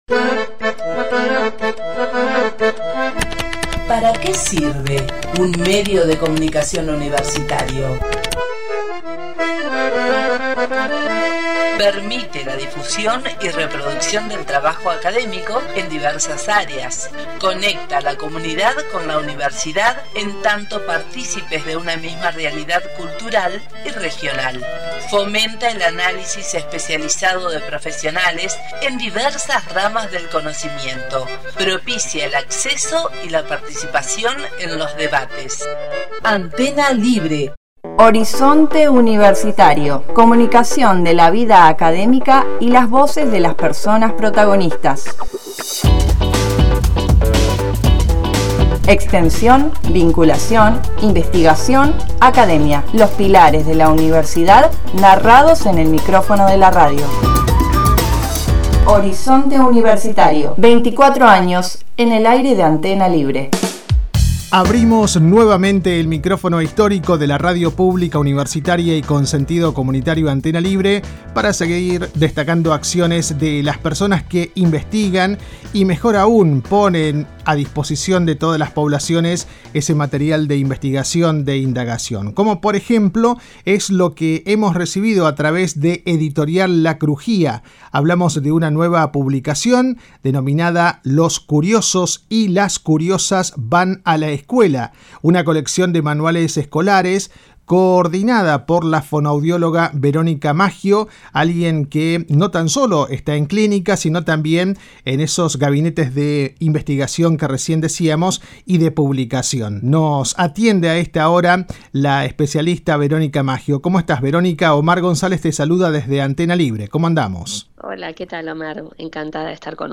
Durante la charla con Horizonte Universitario